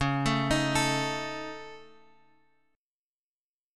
C# Suspended 2nd Sharp 5th